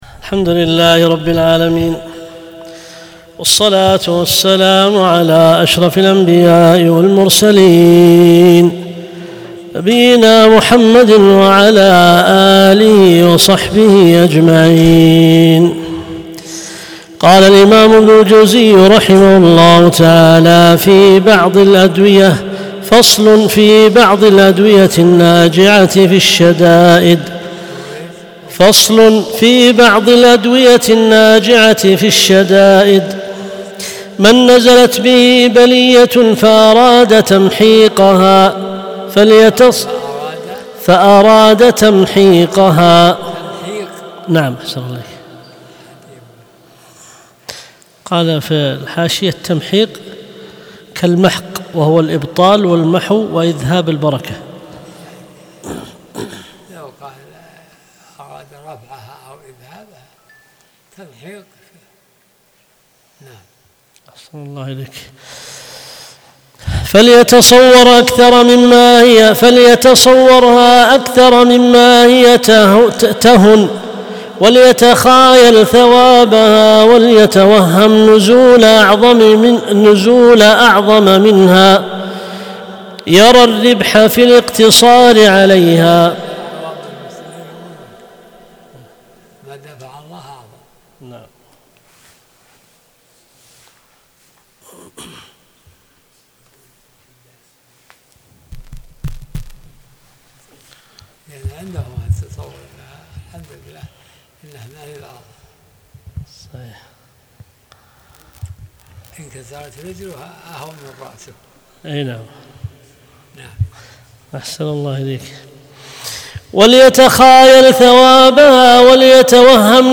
درس الأربعاء 34